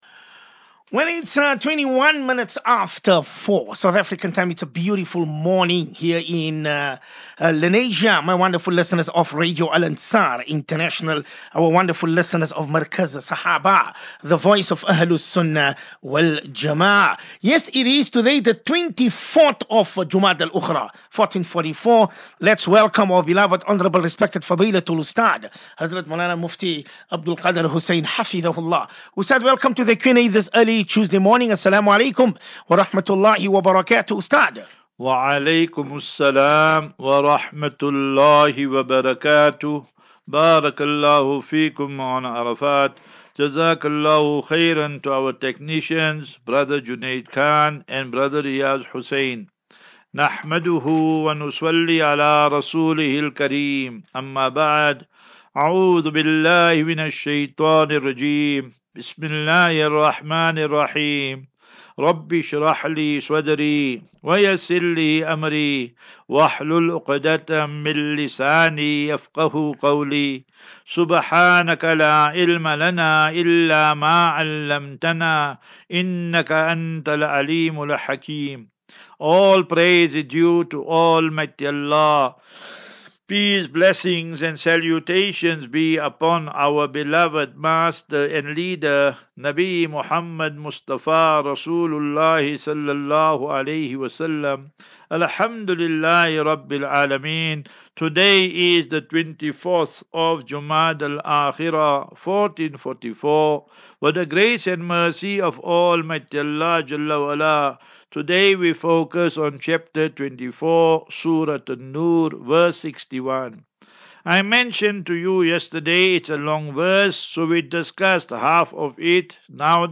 View Promo Continue Install As Safinatu Ilal Jannah Naseeha and Q and A 17 Jan 17 Jan 23- Assafinatu-Illal Jannah 36 MIN Download